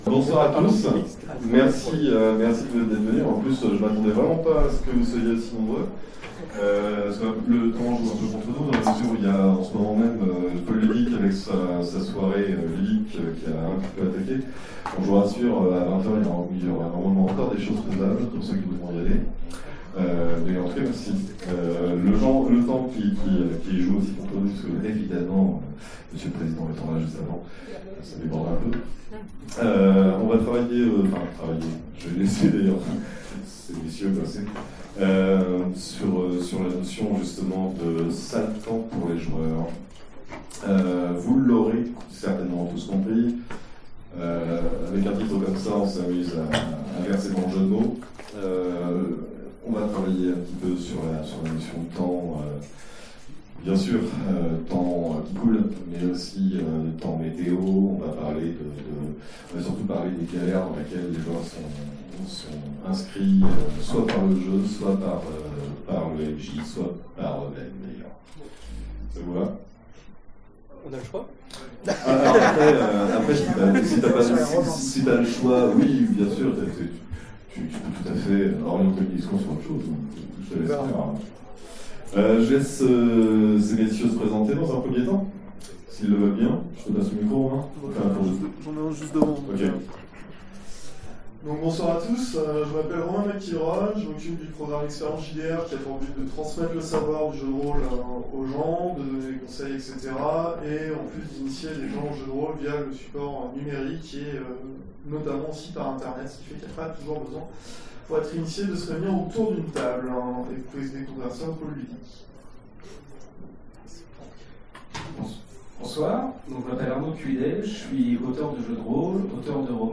Utopiales 2017 : Conférence Sale temps pour les joueurs